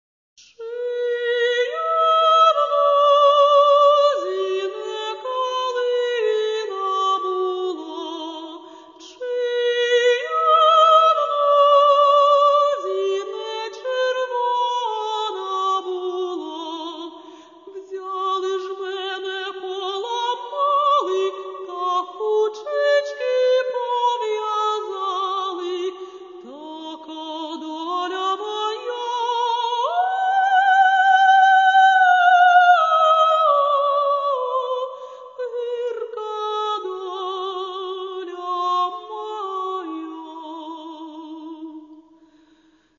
музика: народна пісня